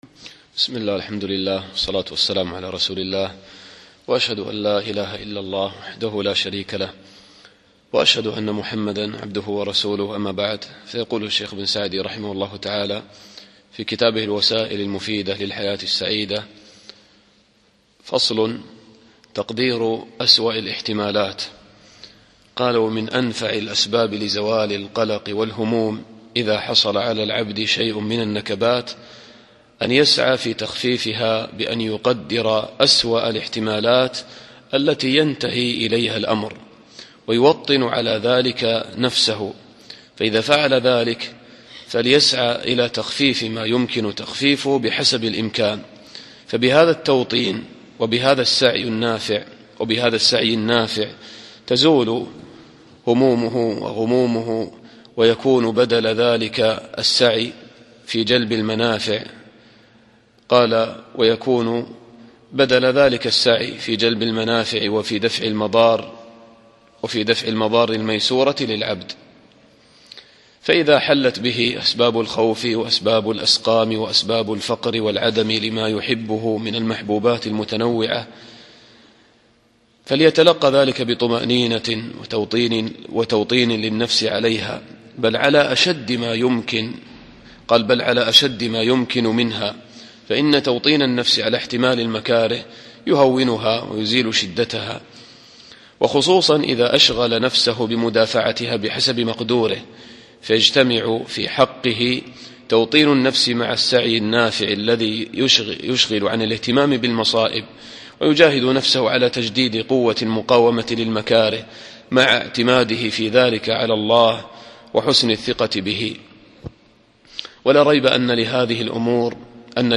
الدرس الثاني عشر